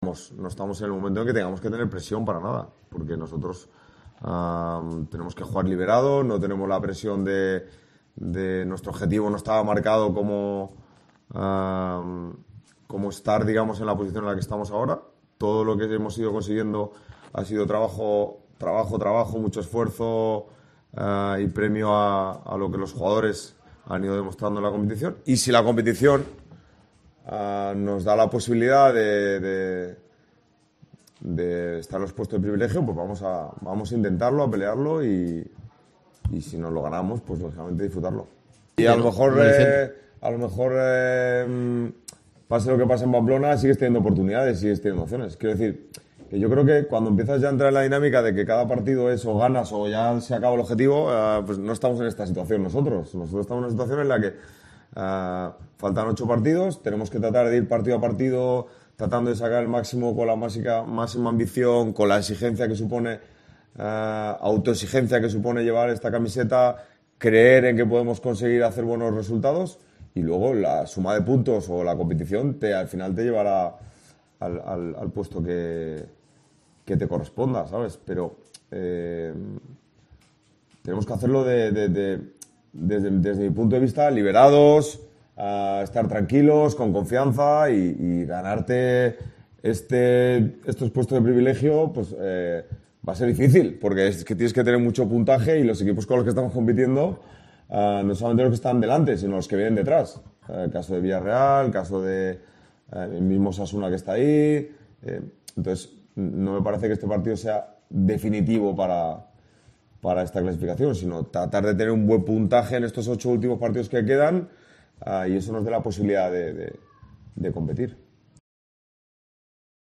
"Hay que jugar con esa tranquilidad y esa confianza que nos da el trabajo demostrado durante todo el año y hay que sentirse liberados, pero a la vez con la intención de competir al máximo y sabiendo la dificultad, porque cuando vas a Pamplona ya sabes que tienes que llevar las orejas tiesas", explicó el técnico vallisoletano en rueda de prensa.